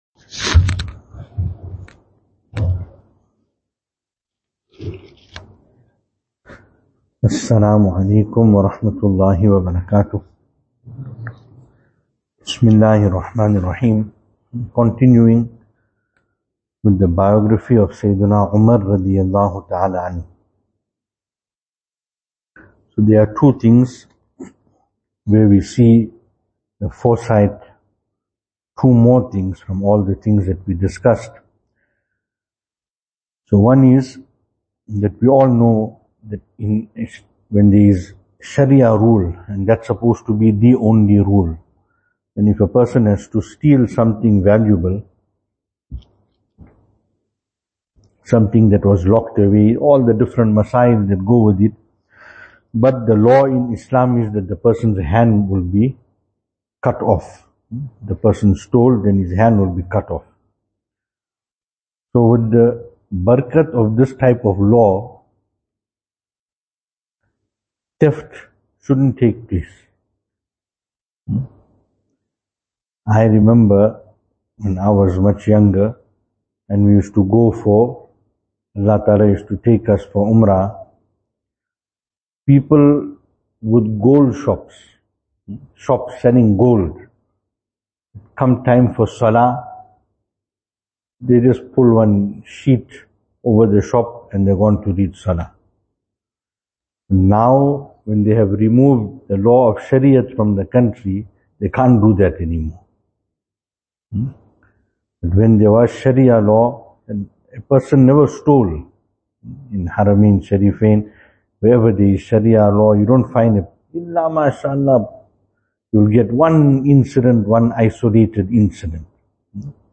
Venue: Albert Falls , Madressa Isha'atul Haq
Service Type: Majlis